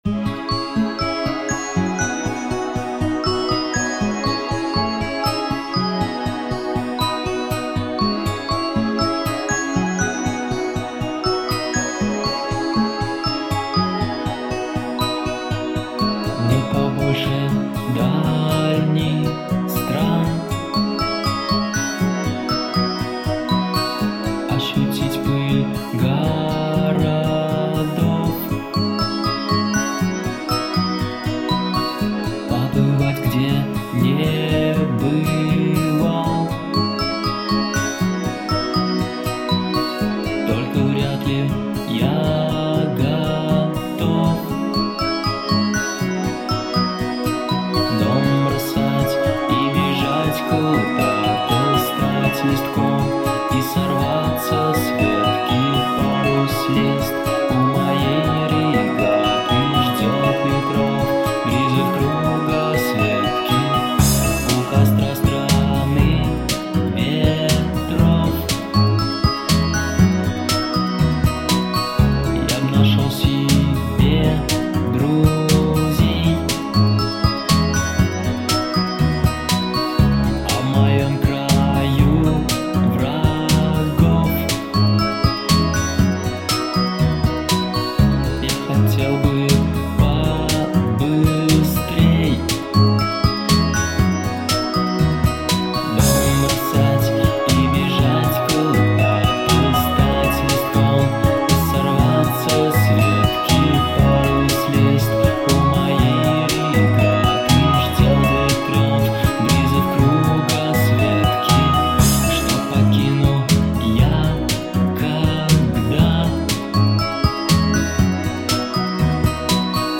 Песня.